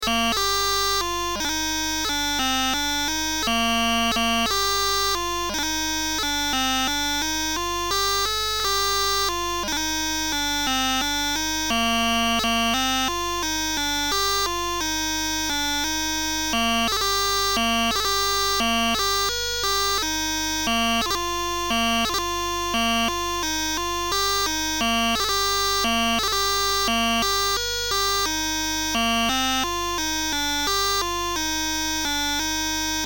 Valse Ecossaise